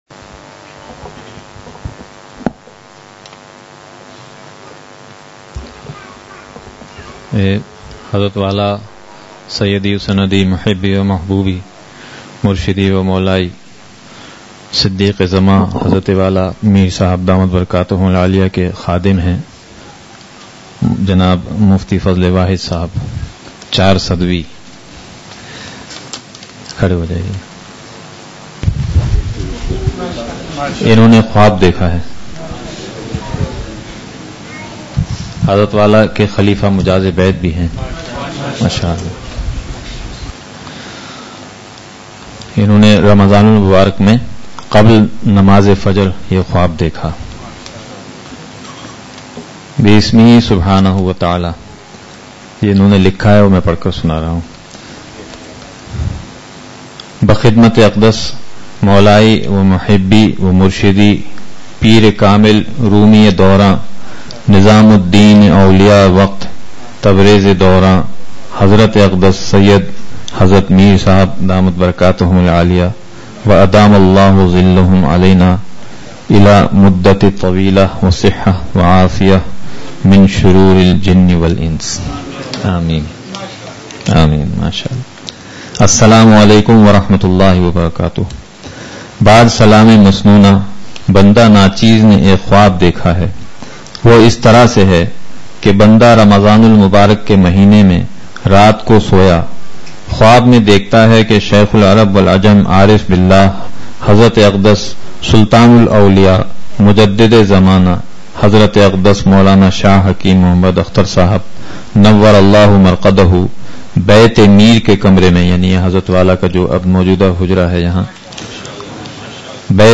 Please download the file: audio/mpeg مجلس محفوظ کیجئے اصلاحی مجلس کی جھلکیاں خلاصۂ مجلس: ۔
آخر میں درد بھری دُعا بھی فرمائی جس میں خصوصی طور پر پاکستان کی حفاظت اور شرور و فتن سے اس کی حفاظت کی دعا بھی فرمائی۔